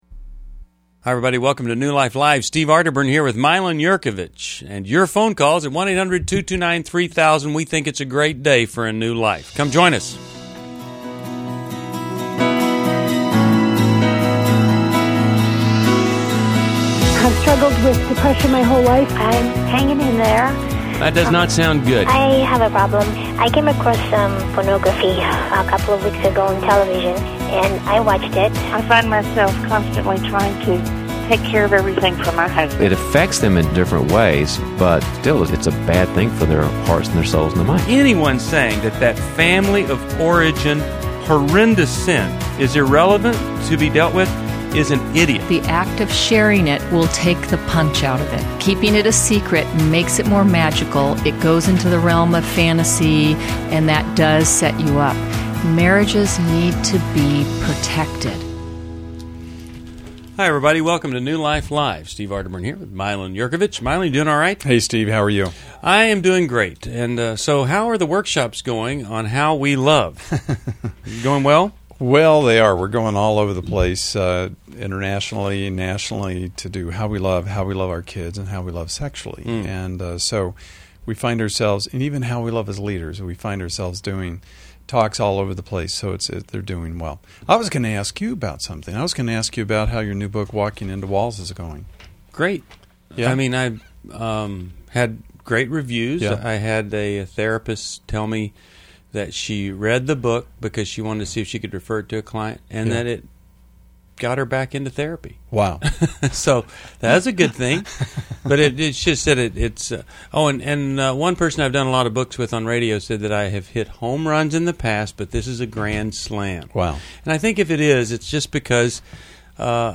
Explore relationship dynamics, parenting challenges, and mental health in New Life Live: November 8, 2011, as experts tackle real caller dilemmas.